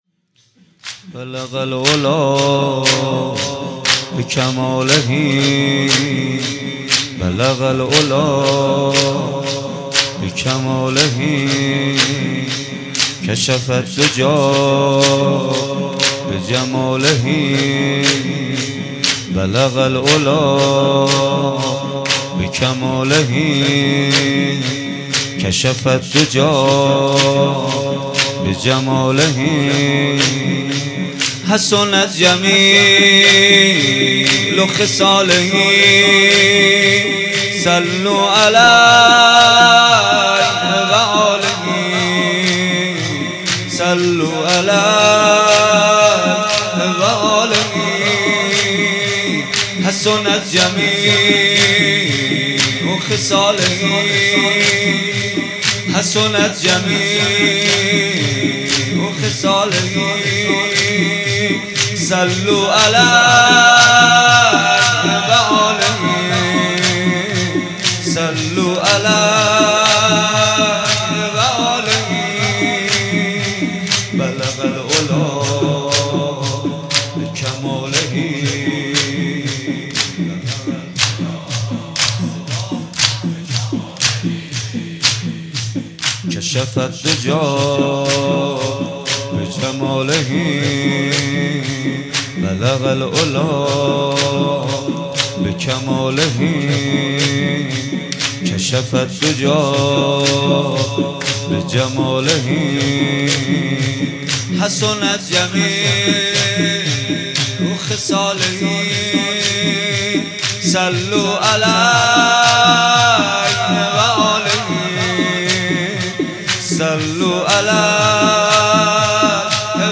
بيست و نهم صفر 95 - زمينه - بلغ العلی بکماله